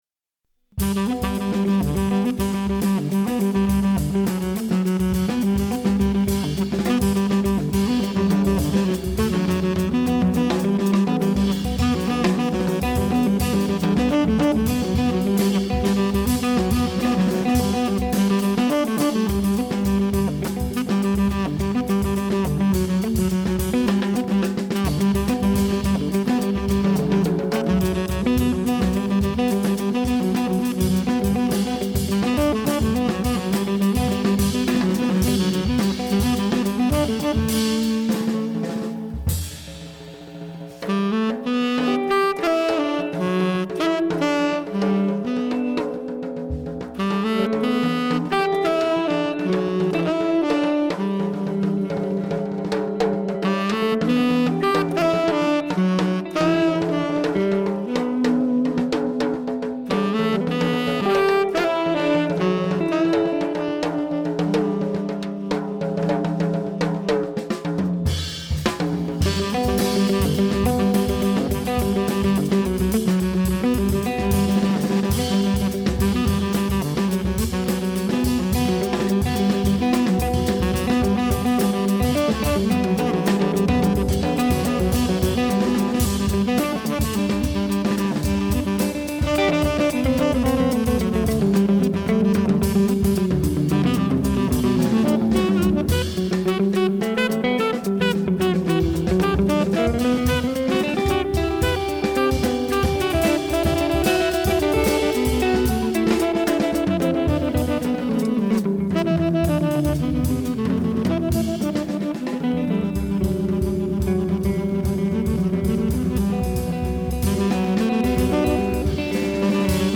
mélodie qui elle même se répète joyeusement.
celtique jazzeux.